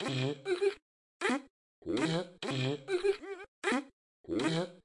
节奏3b tempo99 - 声音 - 淘声网 - 免费音效素材资源|视频游戏配乐下载
音序器Flstudio 11.0.Tempo 99 bpm，实验风格。